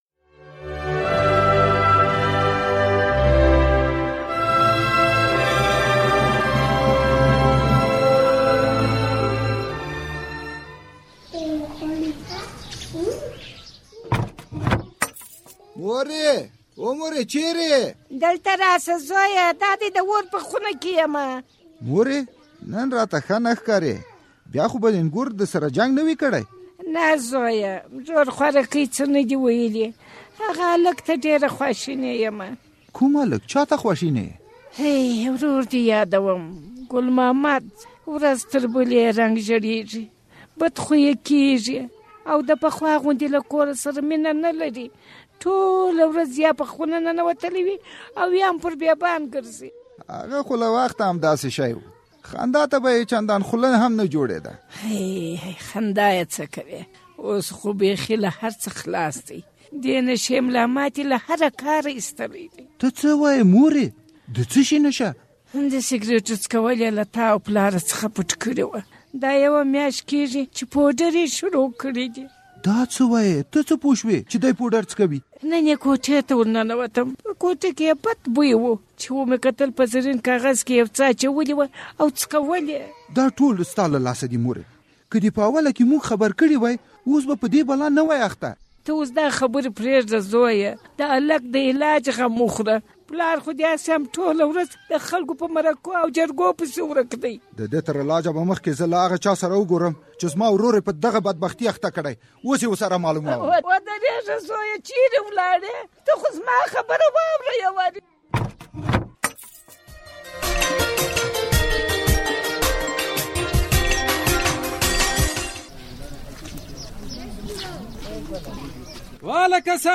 د زهرو کاروان ډرامه